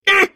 File File history File usage SpaceAh!.wav  (WAV audio file, length 0.4 s, 706 kbps overall) This file is an audio rip from a(n) Windows game.